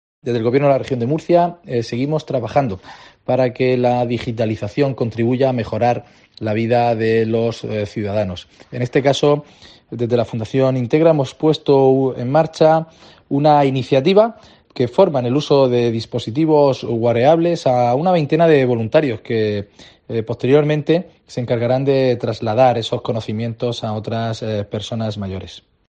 Javier Martínez Gilabert, director general de Informática y Transformación Digital